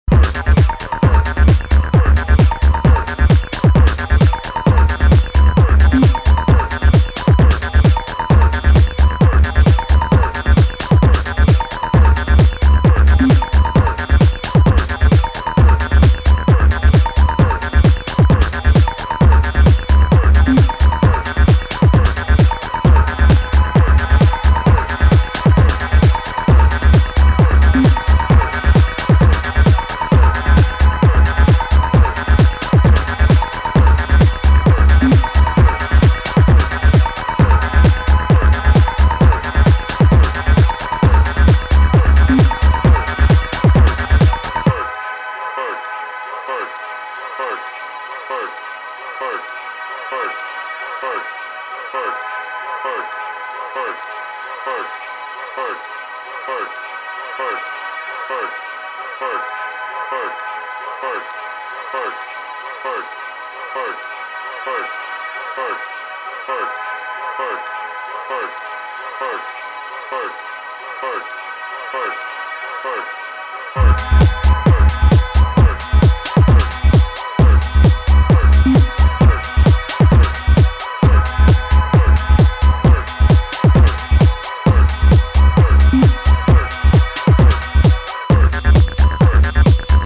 banging remixes